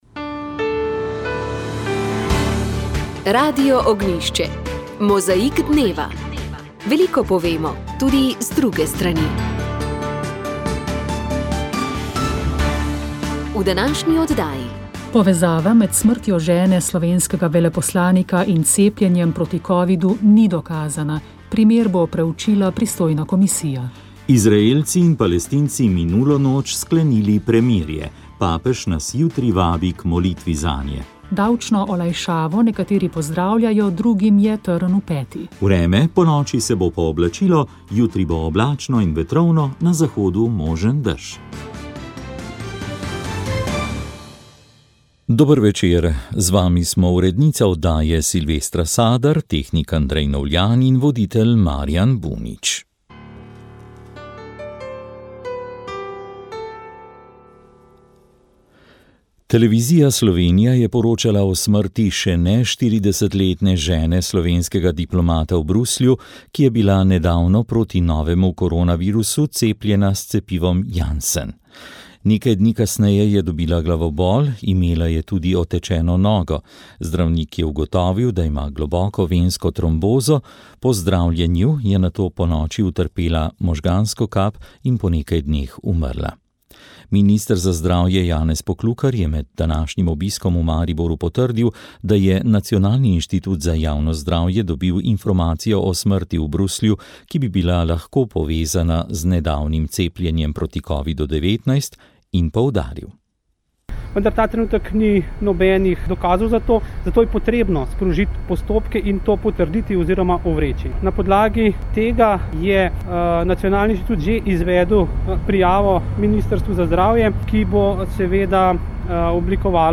Radio Ognjišče info novice Informativne oddaje VEČ ...